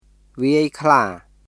[ヴィアイ・クラー　viˑəi kʰlaː]